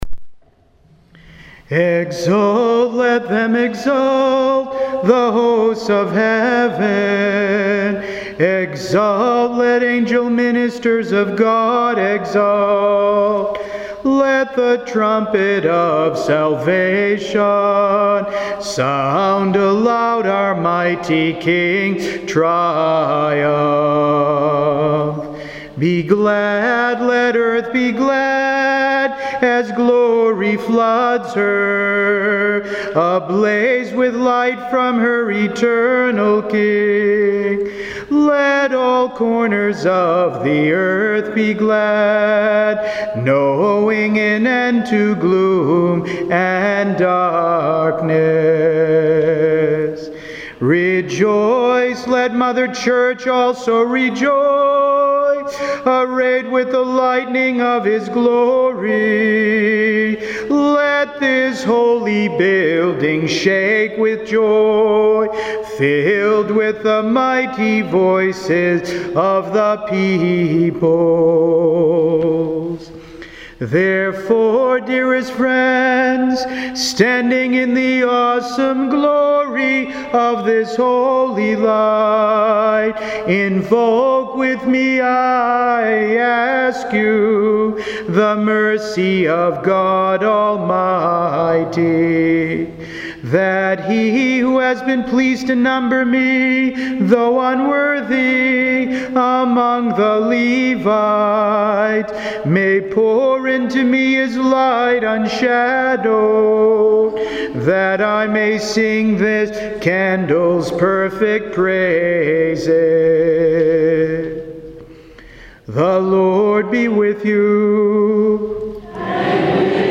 Easter Vigil 2014